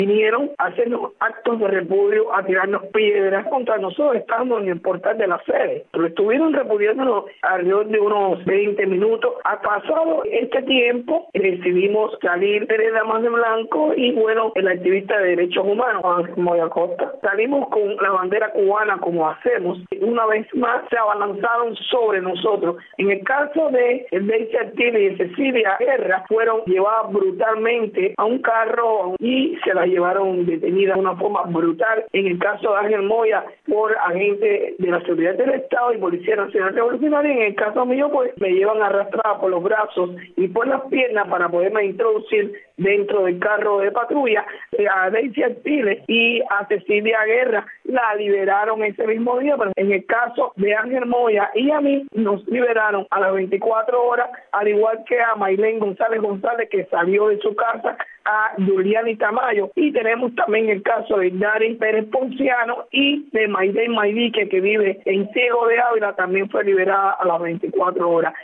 Berta Soler, líder de las Damas de Blanco, y otras activistas arrestadas el domingo fueron liberadas en la tarde del lunes, después de permanecer más de 24 horas en diferentes unidades de la policía en la capital cubana. Este fue su testimonio para Radio Martí.